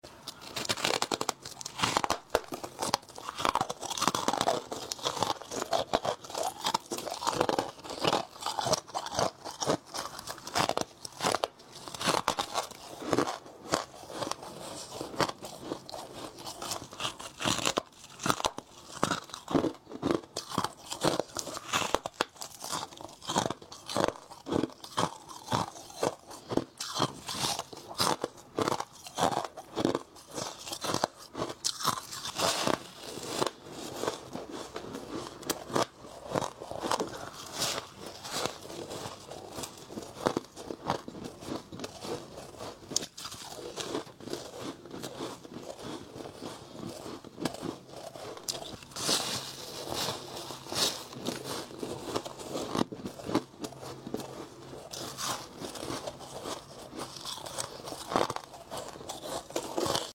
Soft Freezer Frost Ice Asmr Sound Effects Free Download